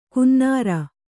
♪ kunnāra